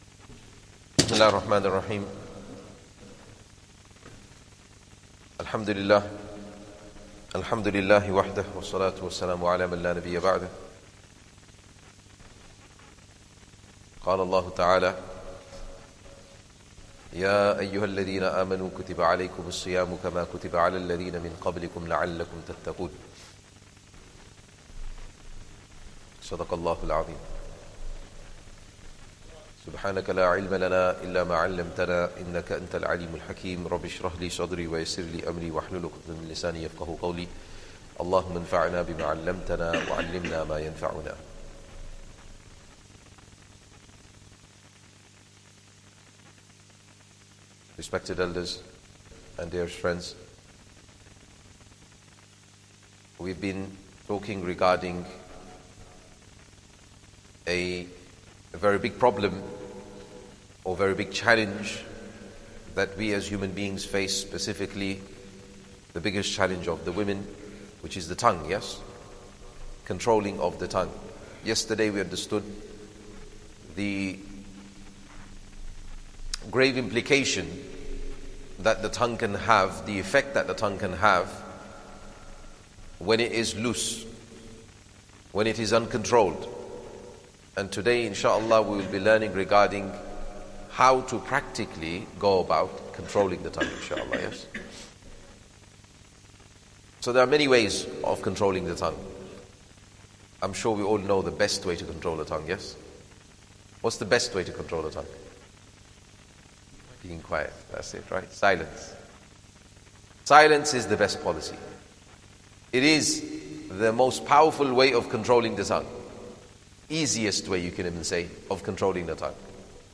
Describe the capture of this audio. Masjid Adam, Ilford